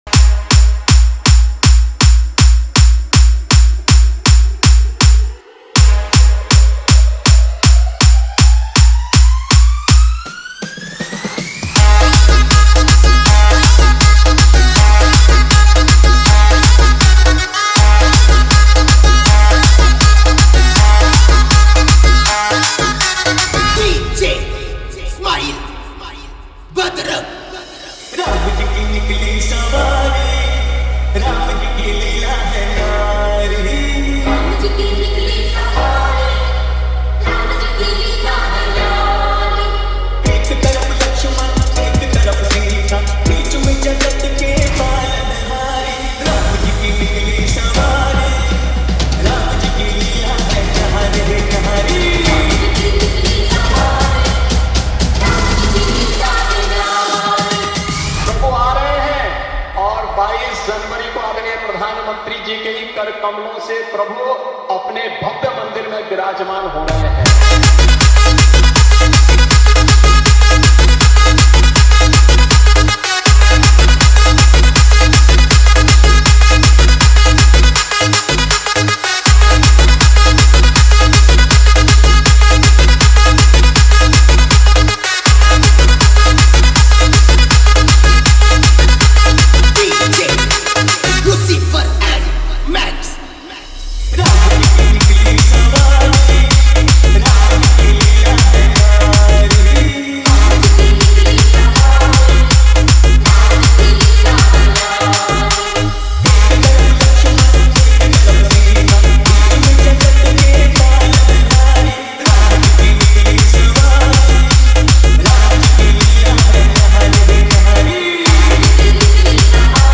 Ram Navami Special Dj Songs Download